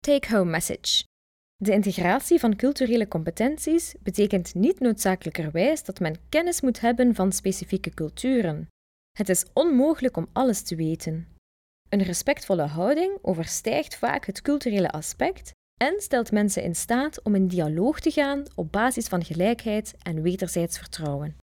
Bericht om mee naar huis te nemen Narration audio (MP3) Narration audio (OGG) De integratie van culturele competenties betekent niet noodzakelijkerwijs dat men kennis moet hebben van specifieke culturen.